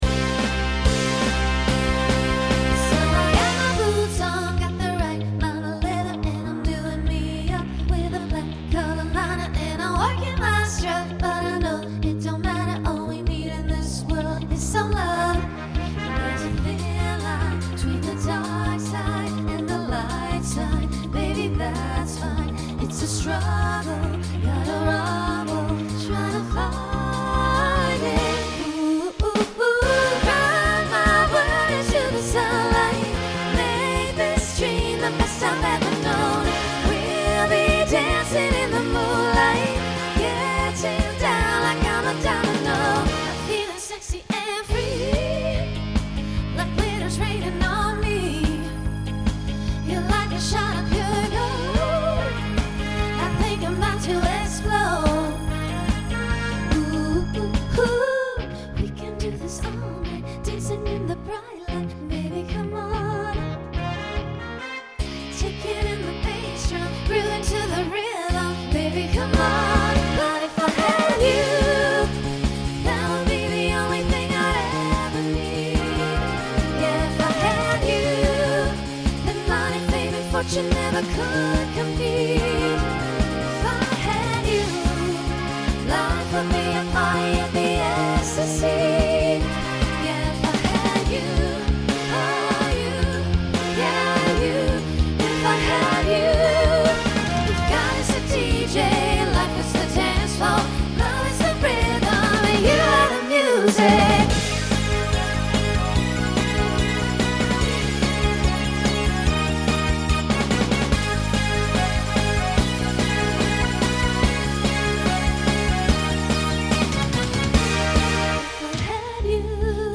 Voicing SSA
Genre Pop/Dance